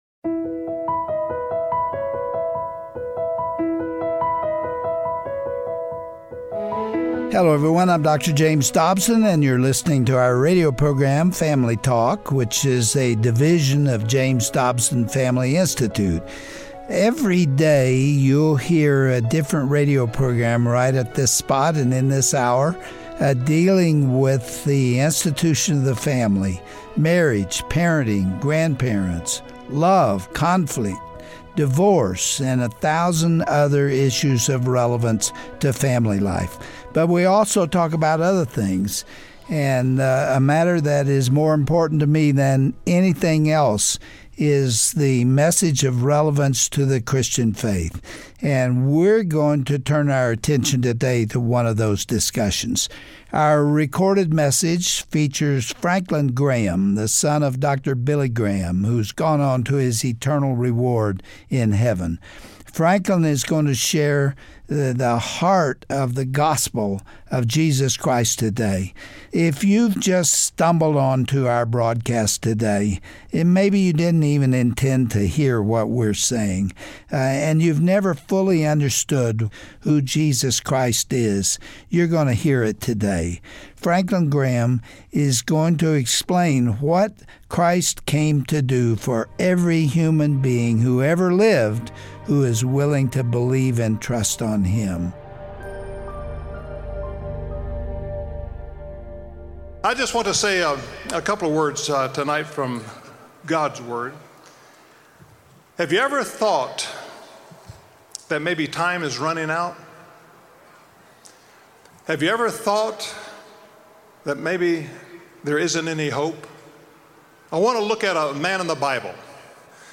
Your Past Erased: A Salvation Message from Rev. Franklin Graham